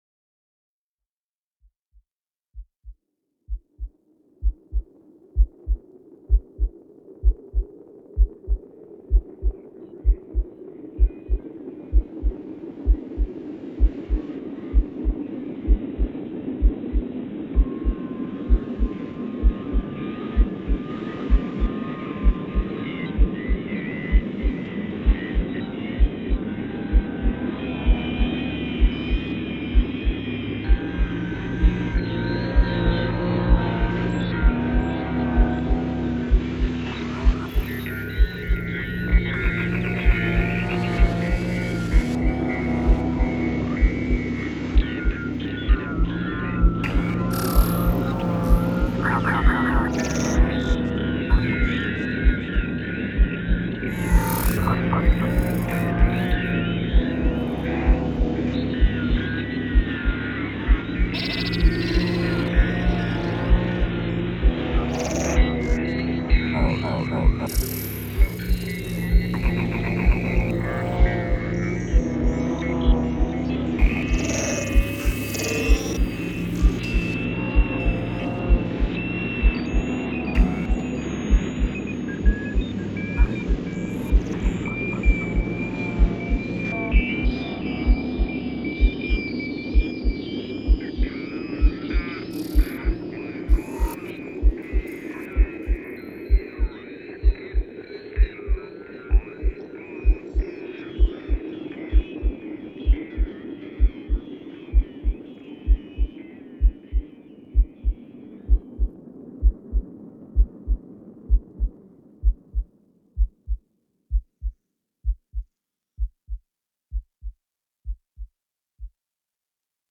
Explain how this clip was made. A4 & OT into Zoom H4n